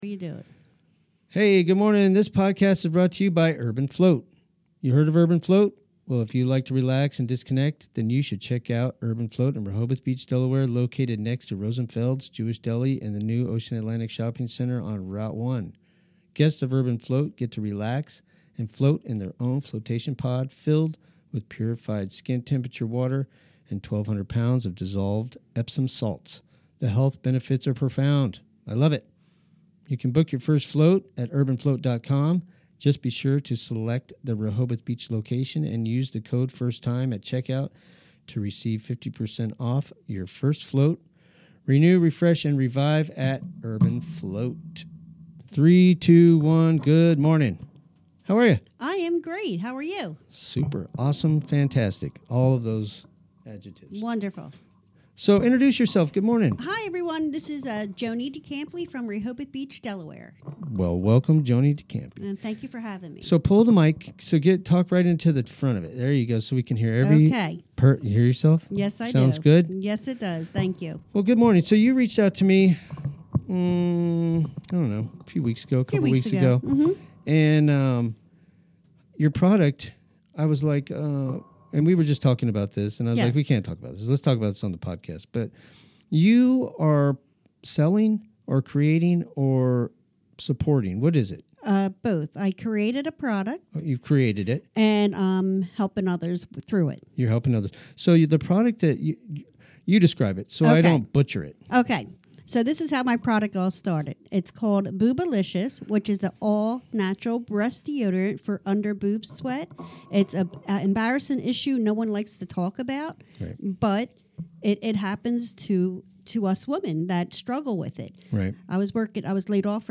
Chatting with local folk